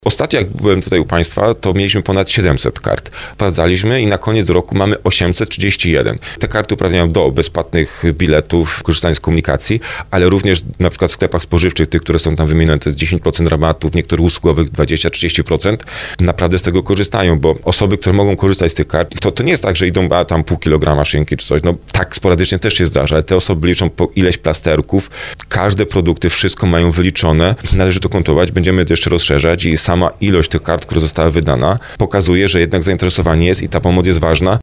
Mówił o tym w audycji Słowo za Słowo wójt gminy Skrzyszów Marcin Kiwior.